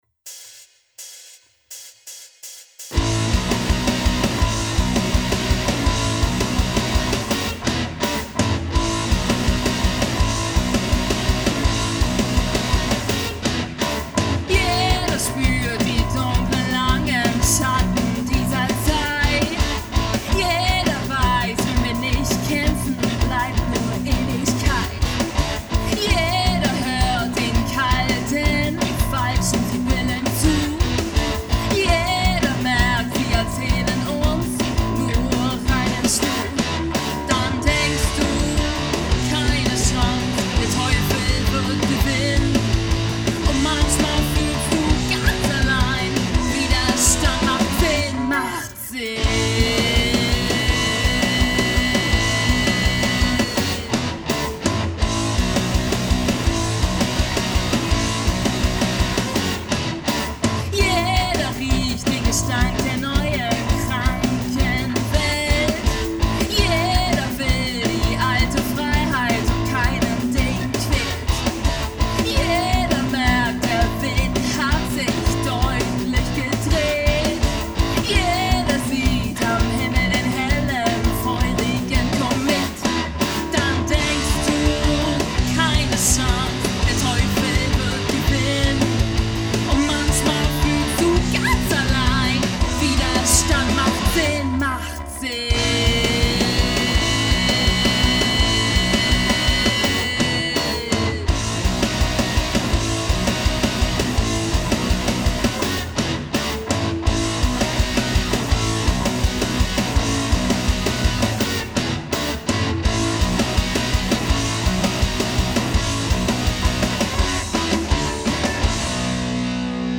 Kein Mainstream !
Rock Band mit Programm, deutschen Texten (kein Kuschelrock!), Proberaum, Studio, Auftrittsmöglichkeiten und mehr, sucht Sängerin/Sänger, gerne auch talentierte Anfänger.